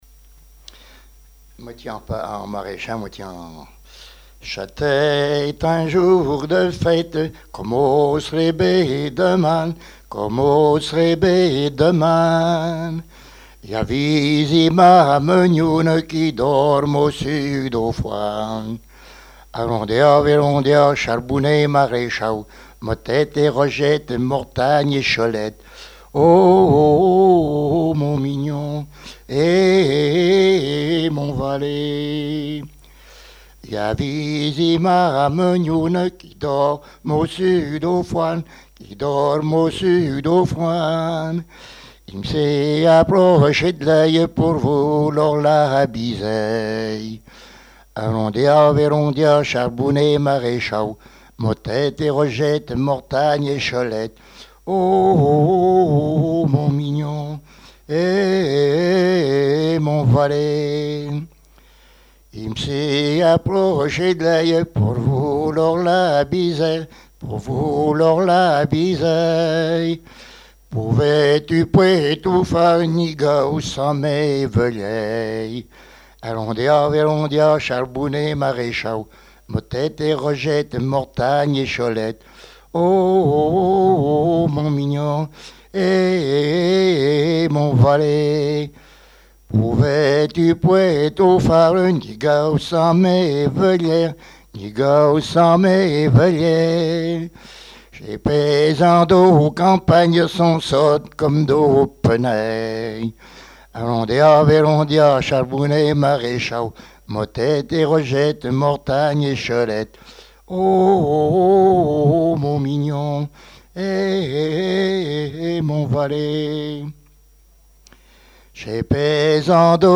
gestuel : à marcher
Genre laisse
Enquête Arexcpo en Vendée
Pièce musicale inédite